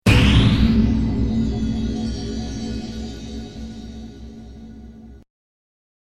ボワーン（95KB） ギャシャーン（70KB）